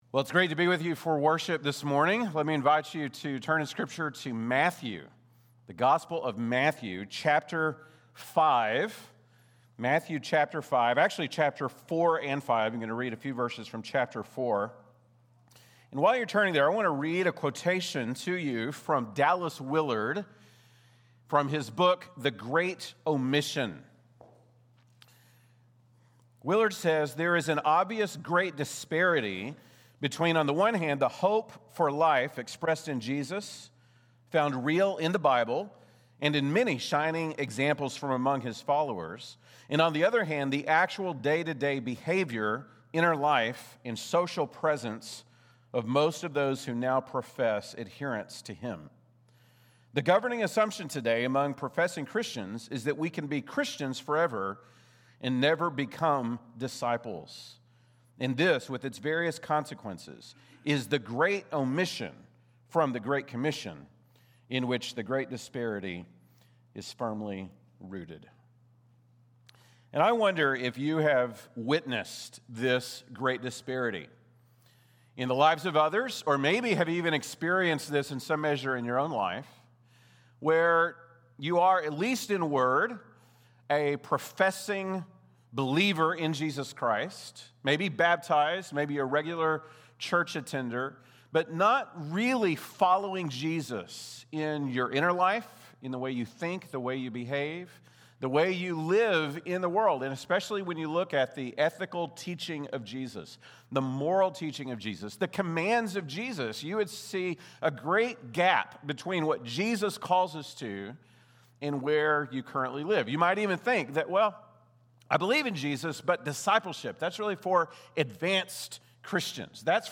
October 5, 2025 (Sunday Morning)